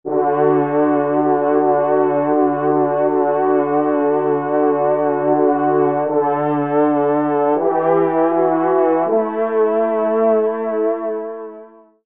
Genre : Fantaisie Liturgique pour quatre trompes
Pupitre de Basse